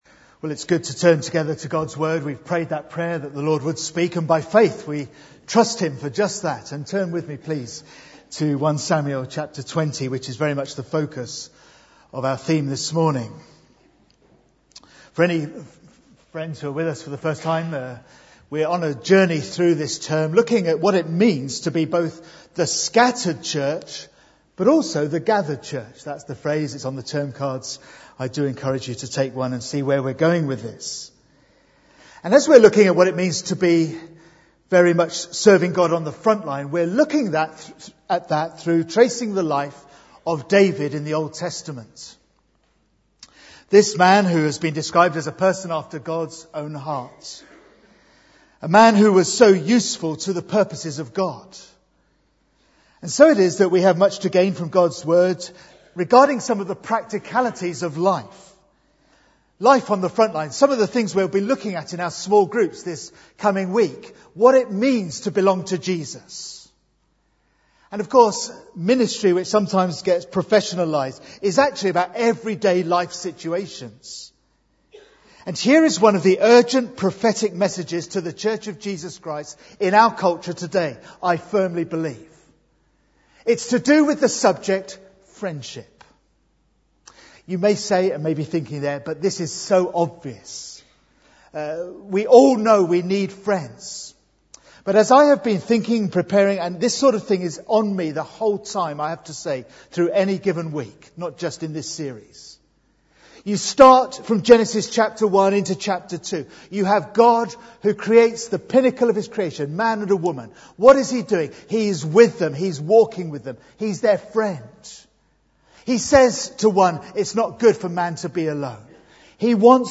Bible Text: 1 Samuel 20:1-17, 30-42 | Preacher